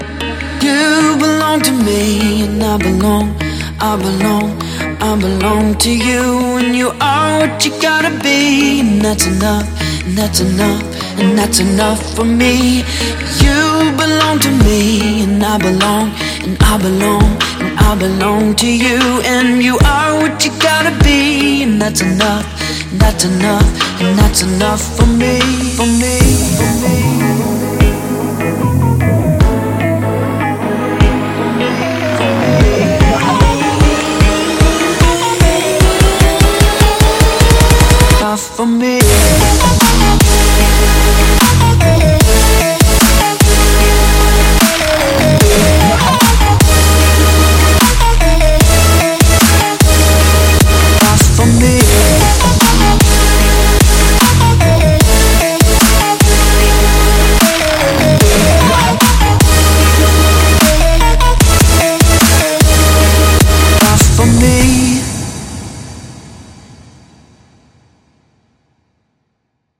100％免版税来自专业男女歌手的声乐。包括干录音和未处理的录音。
所有声音都带有键和BPM标签，并以24bit干.wav文件形式出现。
1100 + Acapellas，Ad-Lib，短语和单词